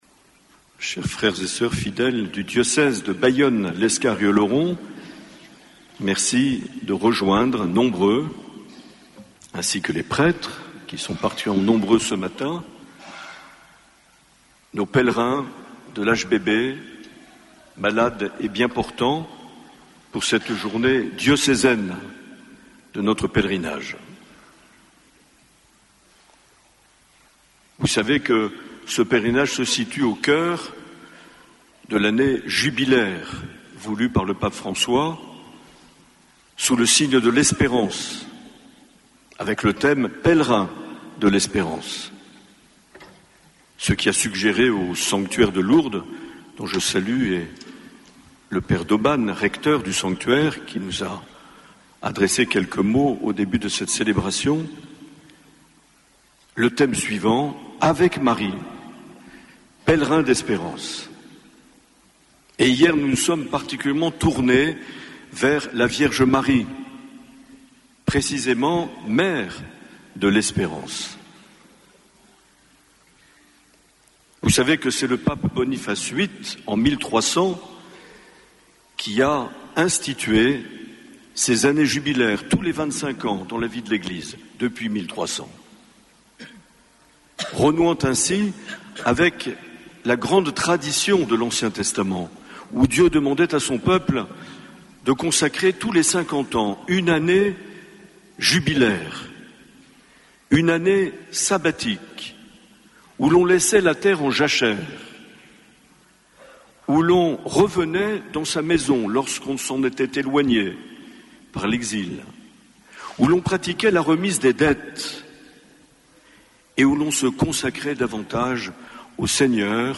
21 septembre 2025 - Pèlerinage diocésain à Lourdes
Homélie de Mgr Marc Aillet